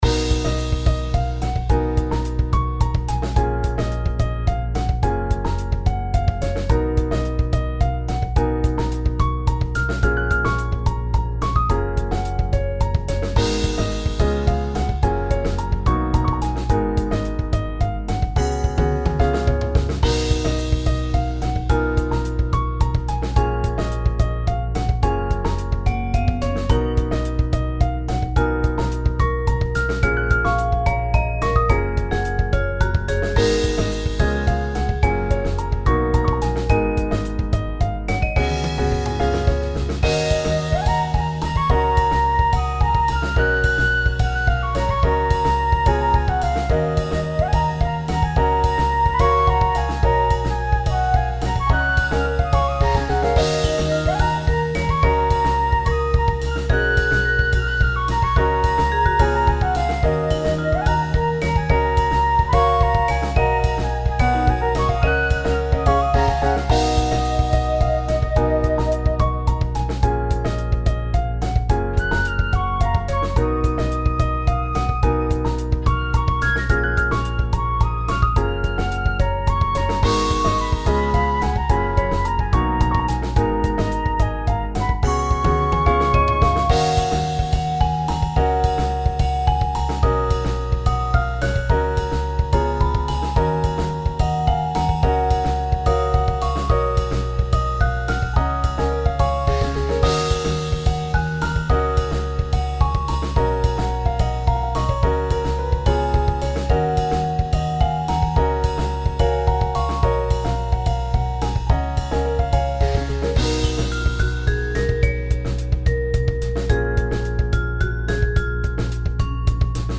ファンタジー系フリーBGM｜ゲーム・動画・TRPGなどに！